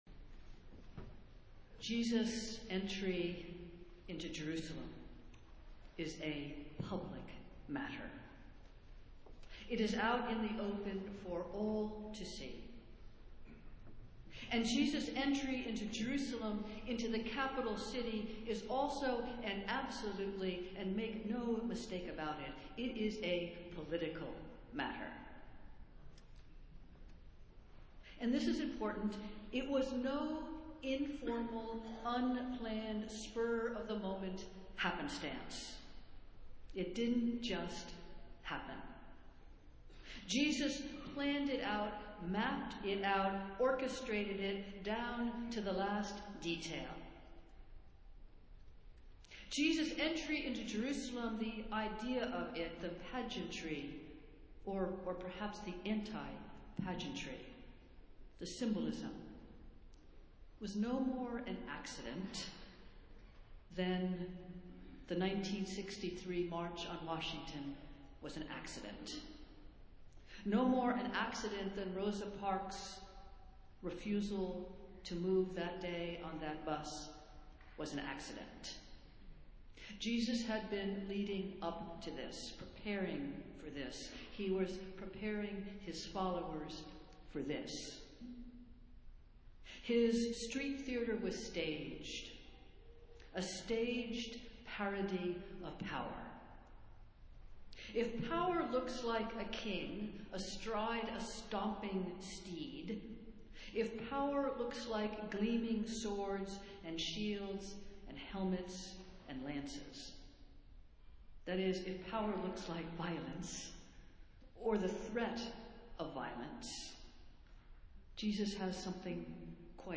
Festival Worship - Palm Sunday